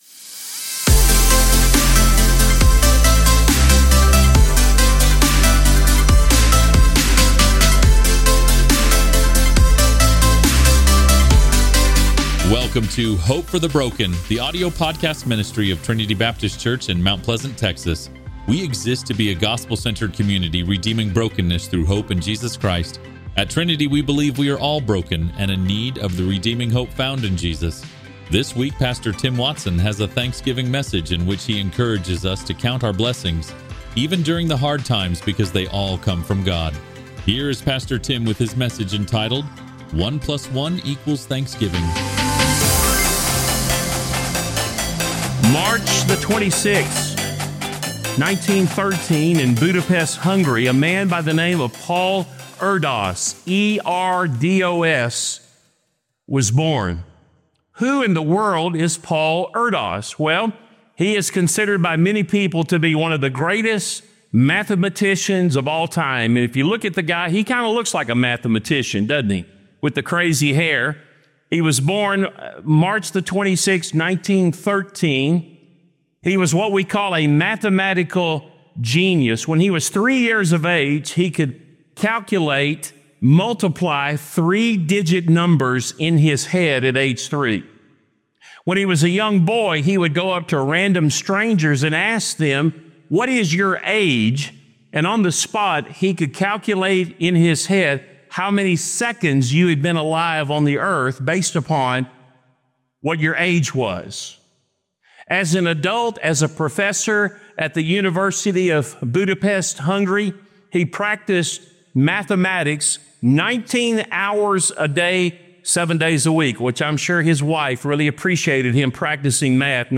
Stand-alone Sermons Passage: 1 Thessalonians 5:16-18